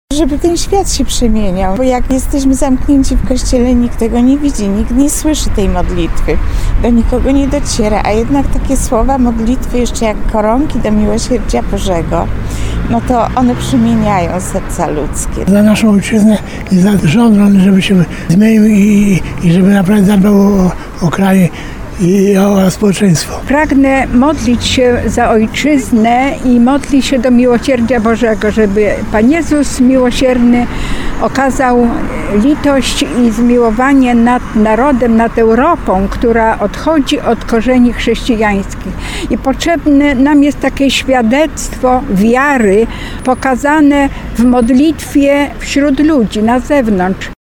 Mieszkańcy zgromadzili się przy Rondzie gen. Nila (Klikowskim), żeby pomodlić się na różańcu. Koronką do Bożego Miłosierdzia chcieli wypraszać łaski dla swoich rodzin i opiekę dla całej Ojczyzny.